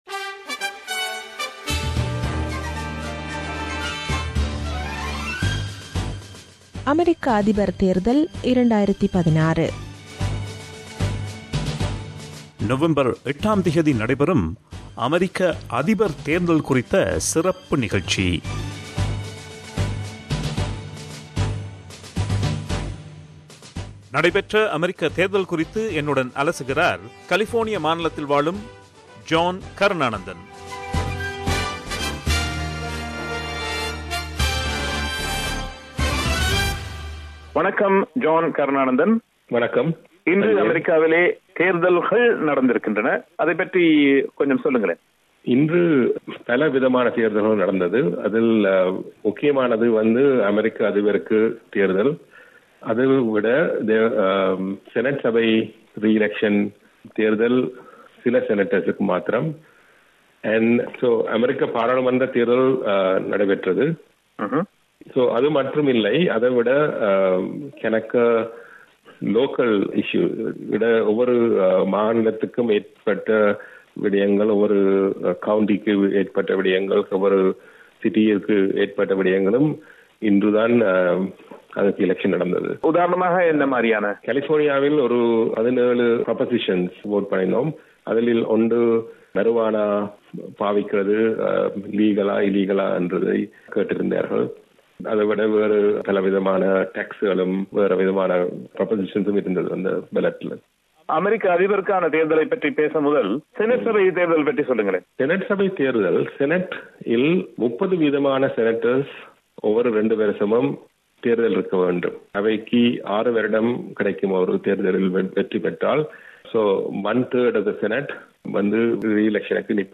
In this segment, we bring to you a review of the election day. We also talk to a political observer and activist